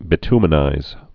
(bĭ-tmə-nīz, -ty-, bī-)